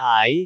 speech
syllable
pronunciation